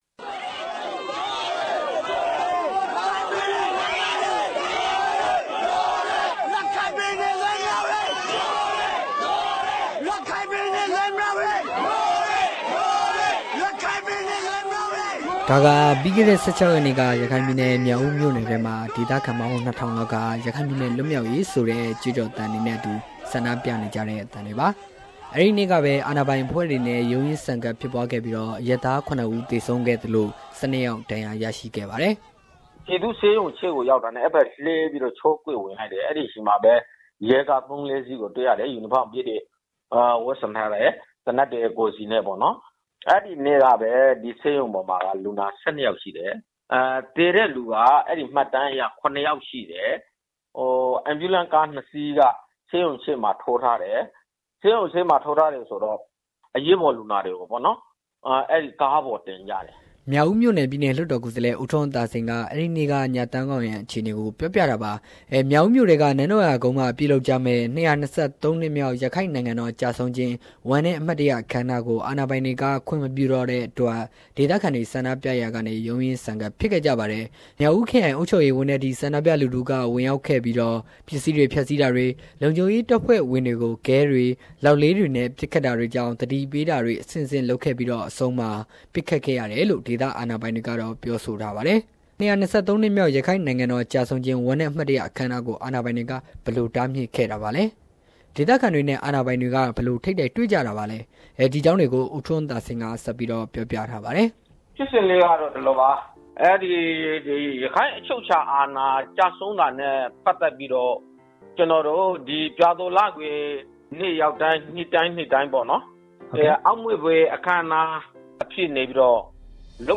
Report on Police killed seven Rakhine protesters in Mrauk-U